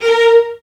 VIOLINS..1-R.wav